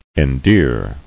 [en·dear]